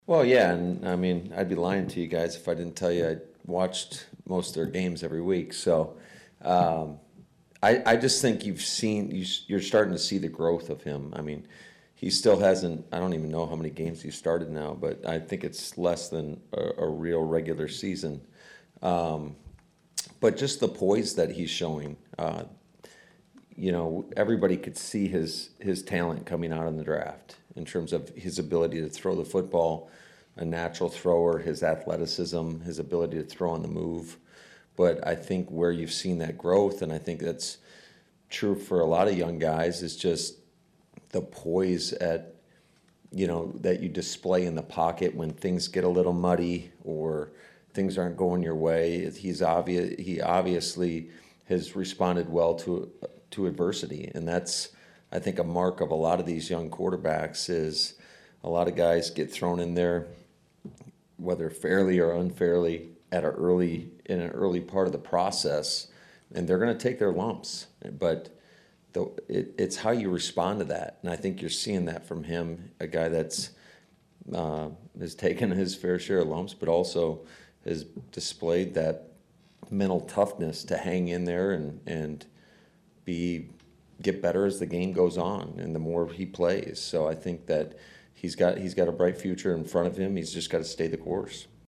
In his pre-practice media session, Matt LaFleur had high praise for Jets receiver Corey Dillon.